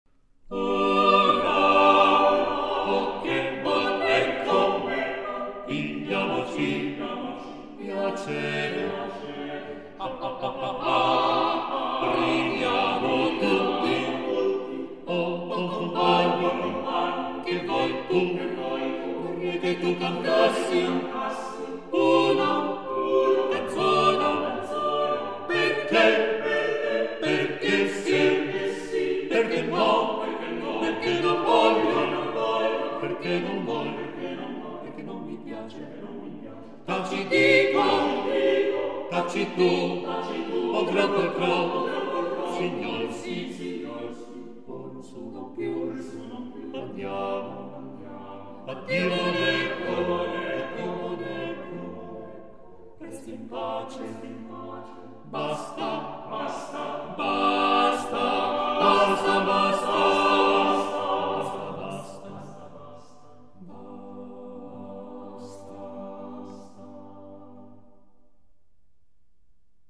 Voicing: SATB Choir